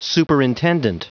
Prononciation du mot superintendent en anglais (fichier audio)